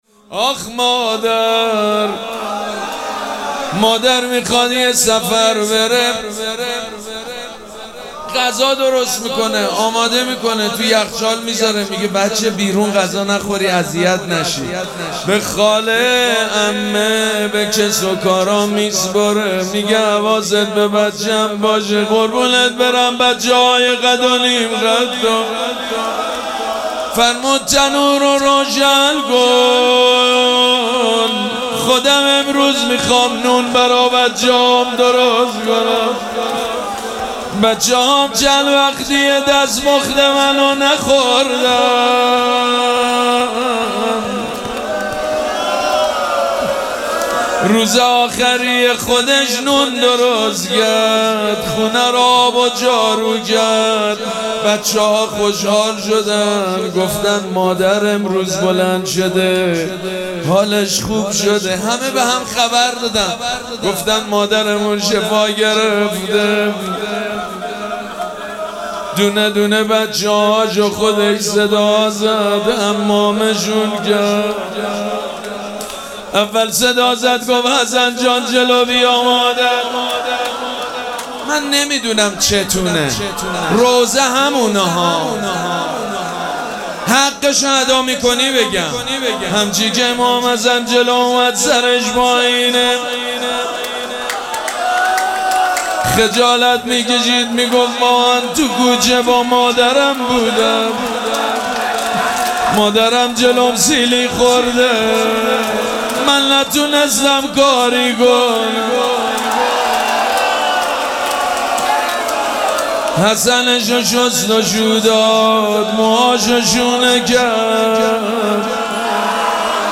روضه حضرت زهرا(س) – محتوانشر